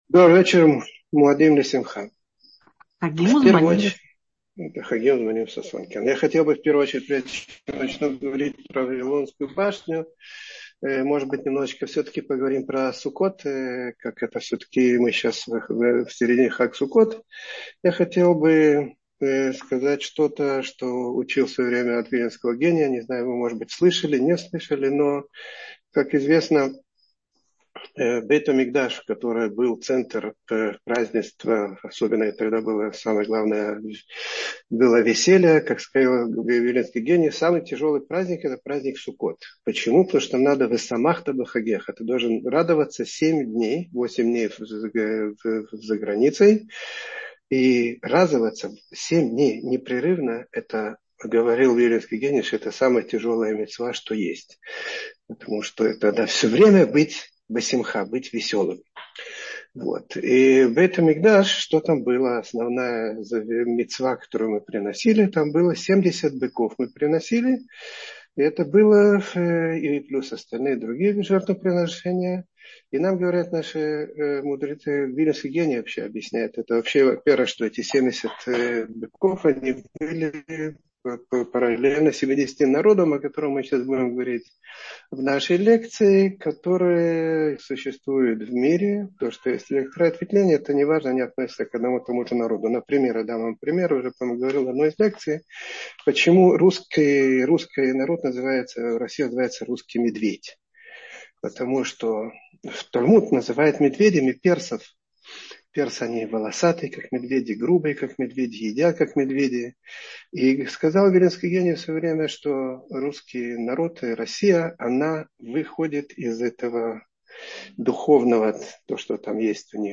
Вавилонская башня тогда и в наши дни — слушать лекции раввинов онлайн | Еврейские аудиоуроки по теме «Танах» на Толдот.ру